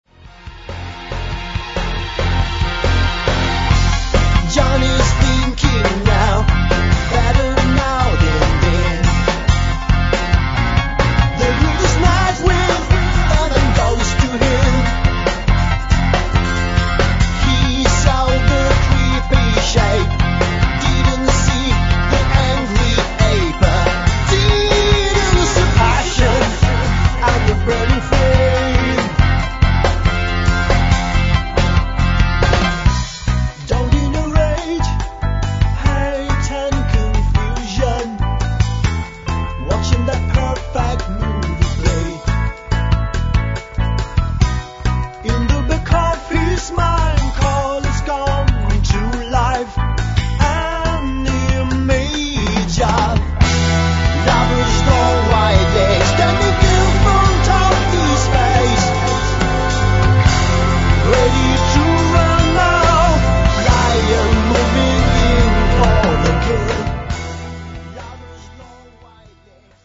現代に蘇る初期ジェネシスワールドfromイタリア
vocals, flute
guitars
bass
piano, mellotron, organ, moogs, synths
drums